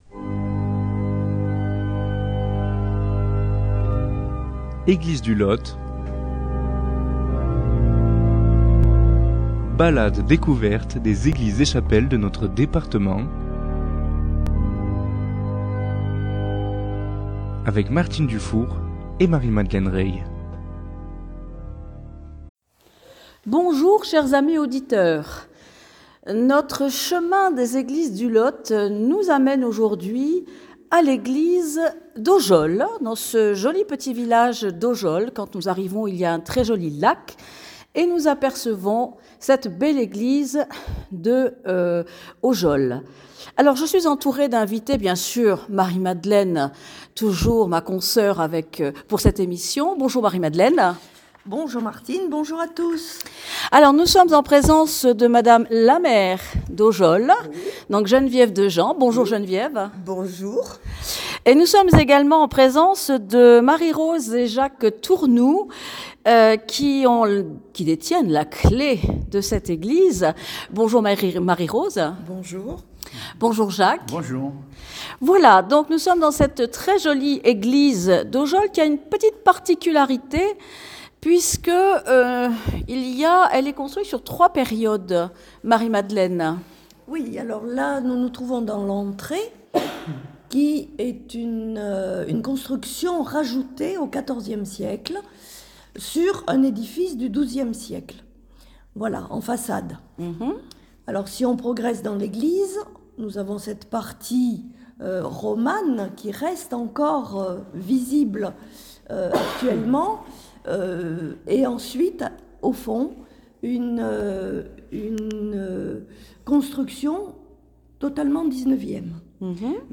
Découvrez avec nous cette belle église aux trésors particuliers et dignes de votre visite.